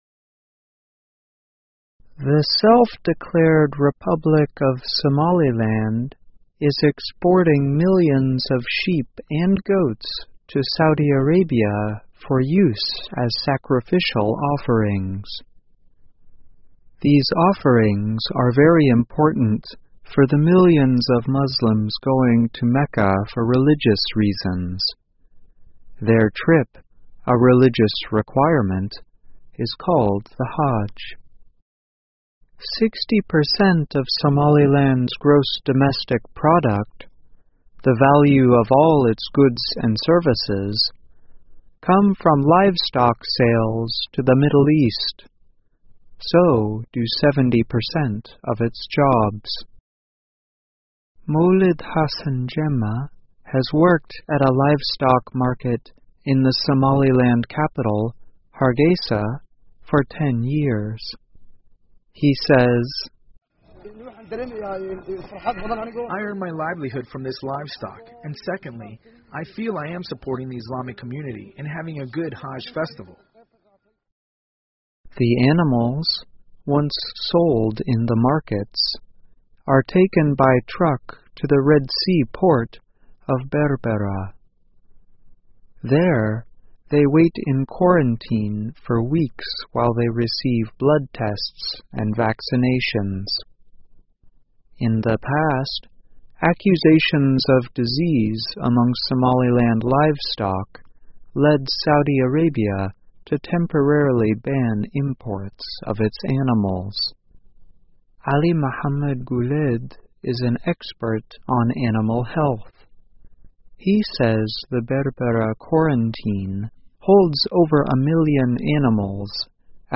VOA慢速英语2016 索马里兰出口牲畜以供朝觐 听力文件下载—在线英语听力室